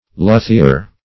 luthier \lu"thi*er\ (l[=oo]"t[-e]*[~e]r), n.